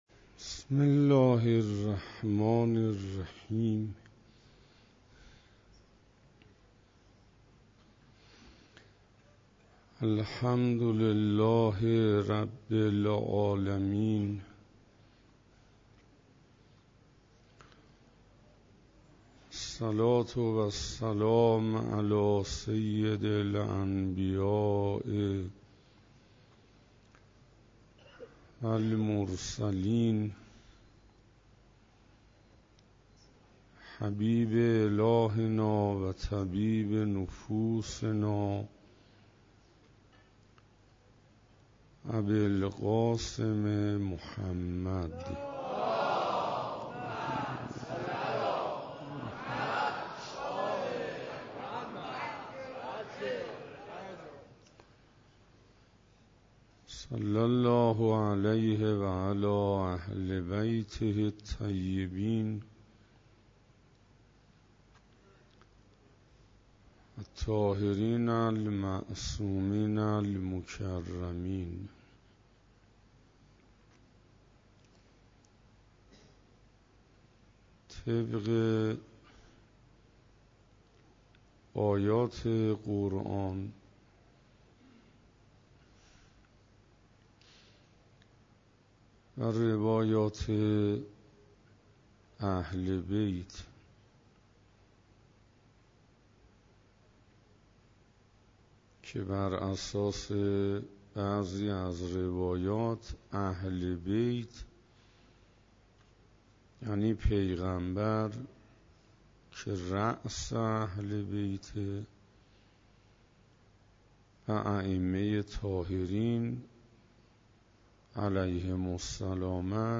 شب 25 رمضان97 - حسینیه همدانی ها - معارف اسلامی